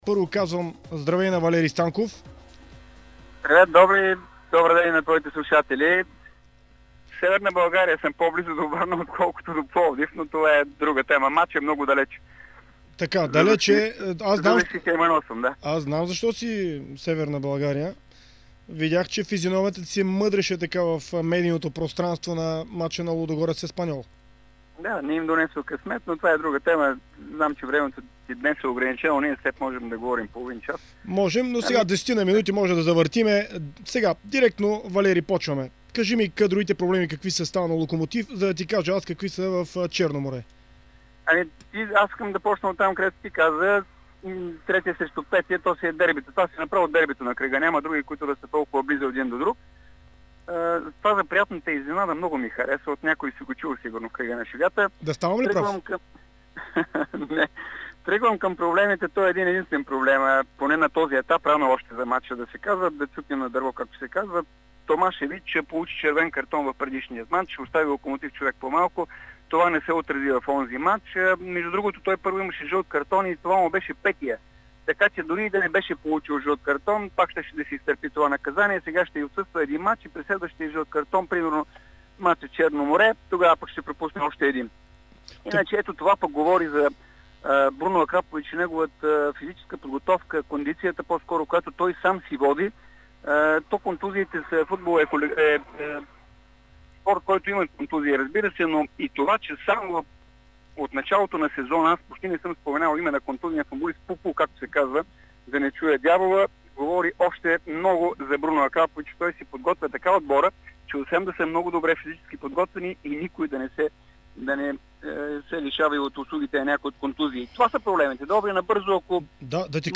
Чуйте в аудио файла какво споделиха двамата в спортното предаване на "Дарик Варна".